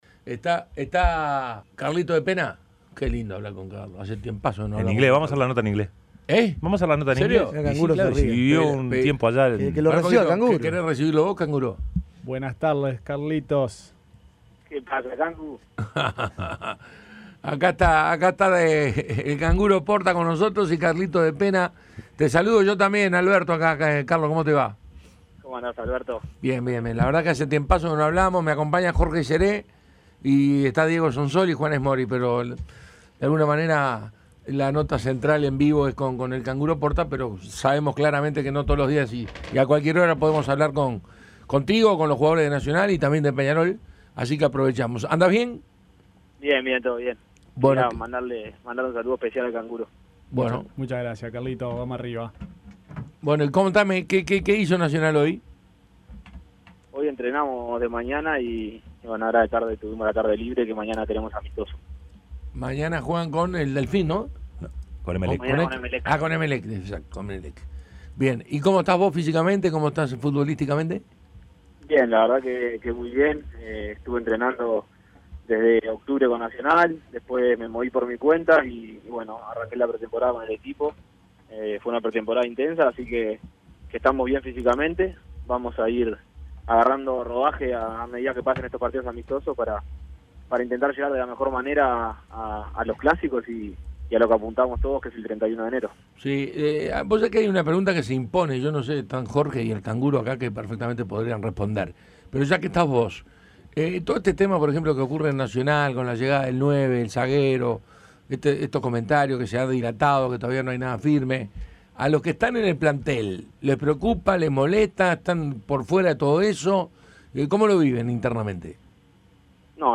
De Pena se refirió a cómo prepara Nacional la temporada que está por comenzar y a cómo se siente en su regreso a la institución. Entrevista completa.